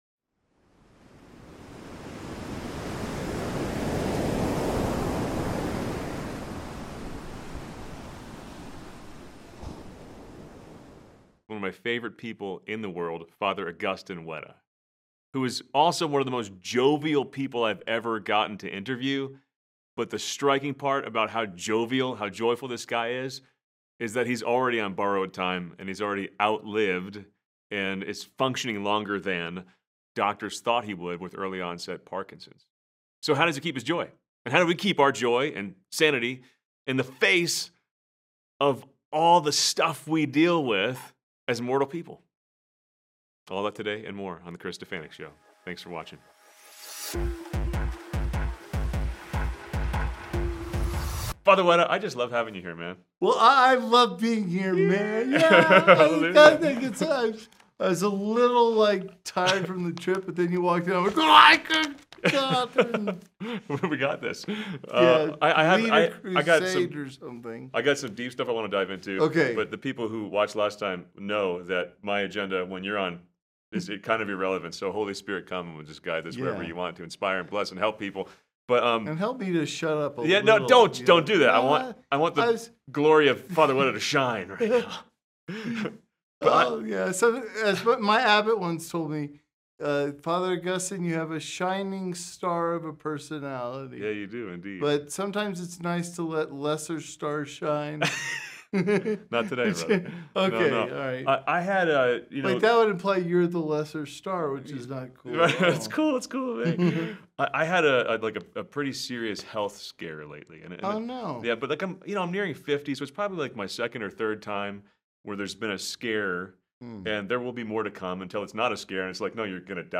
What if your greatest weakness could become your greatest witness? In this powerful, funny, and deeply moving conversation